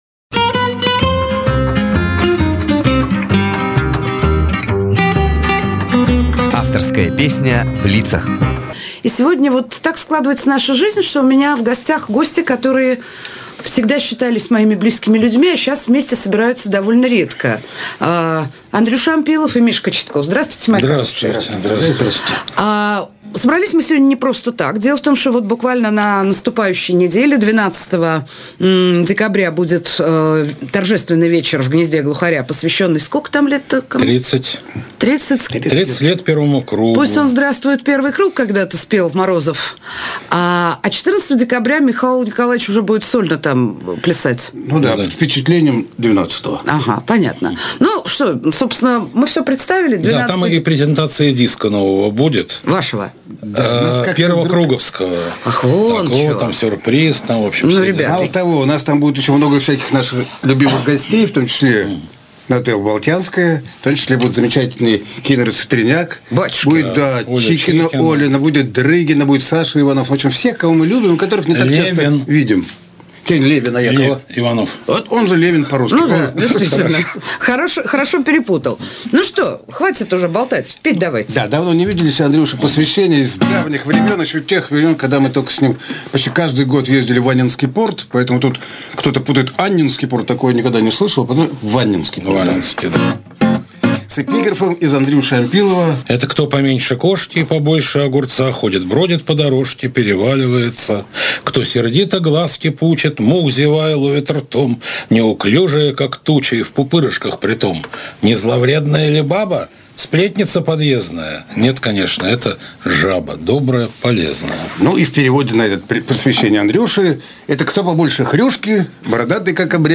барды современной самодеятельной песни
Жанр: Авторская песня